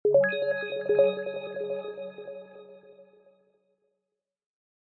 HTC Bildirim Sesleri
Ses çözünürlüğü 192 Kbps / 16 bits ve ses örnekleme oranı 48 Khz olarak üretilen sesler stereo ve yüksek ses çıkışına sahiptir.